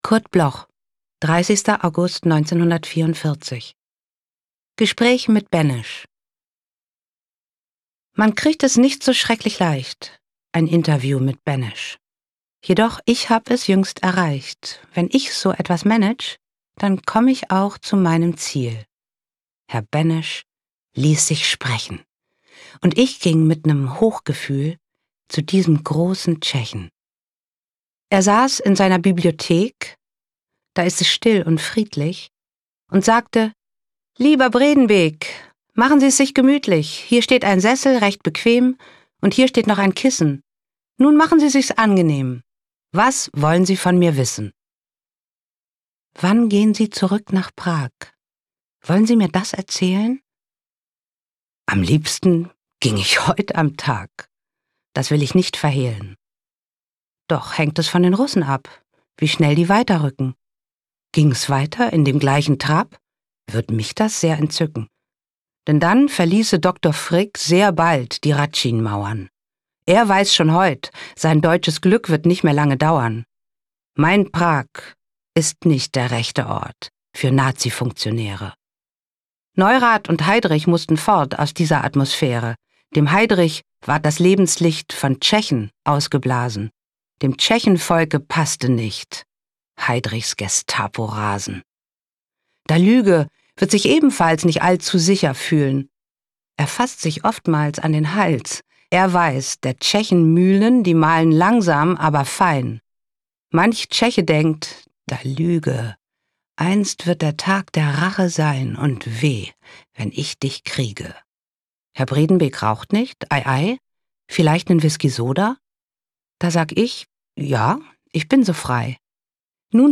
vorgetragen von Maria Schrader